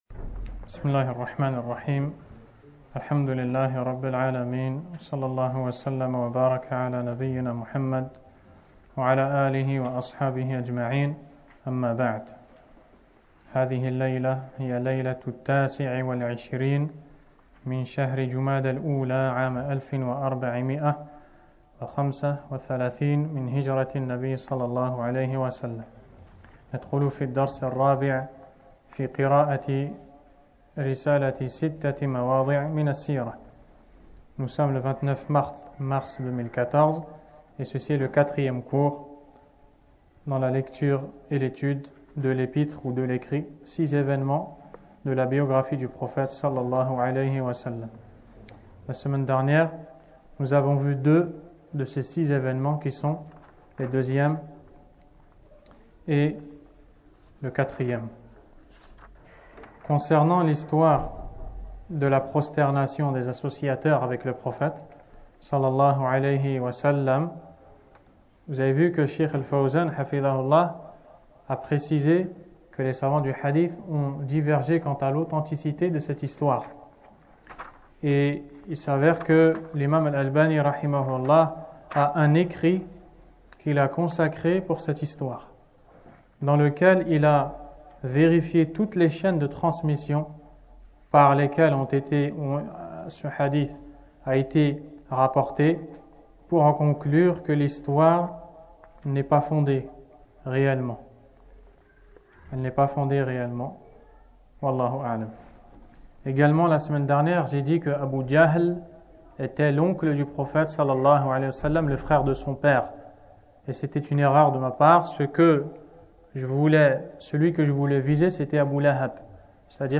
Cours 4 : L'Histoire De L’Émigration Et Ses Leçons